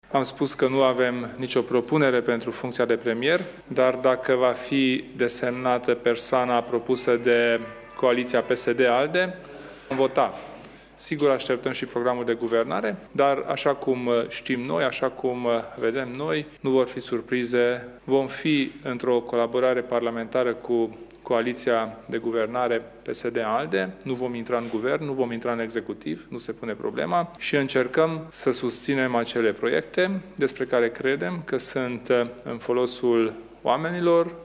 Președintele Uniunii, Kelemen Hunor, a declarat, la finalul consultărilor cu șeful statului de la Palatul Cotroceni, că nu vor intra în Guvern, dar sunt de acord cu propunerea coaliției parlamentare pentru funcția de prim-ministru, Sevil Shhaideh.
UDMR va susține acele proiecte ale coaliției care sunt în folosul oamenilor, a mai spus Kelemen Hunor: